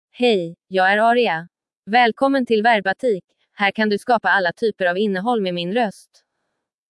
FemaleSwedish (Sweden)
Aria is a female AI voice for Swedish (Sweden).
Voice sample
Listen to Aria's female Swedish voice.
Aria delivers clear pronunciation with authentic Sweden Swedish intonation, making your content sound professionally produced.